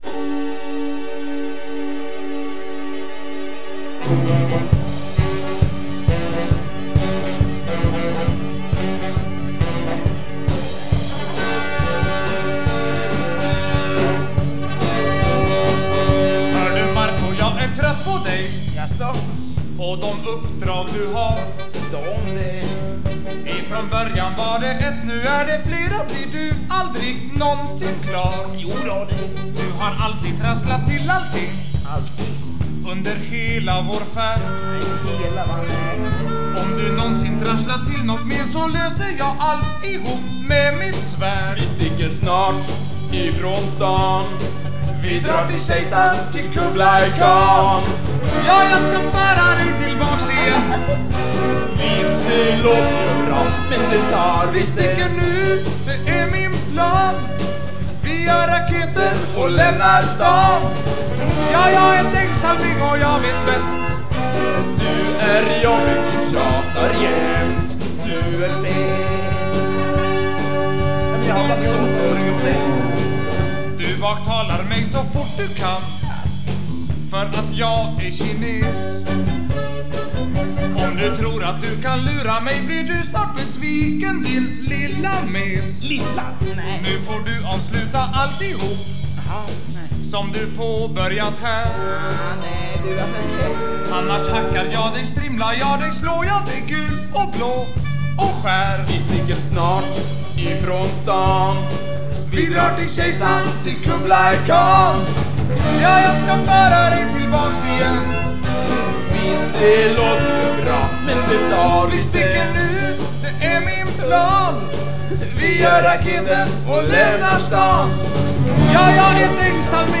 Låtar som av någon anledning valts ut från spex-93.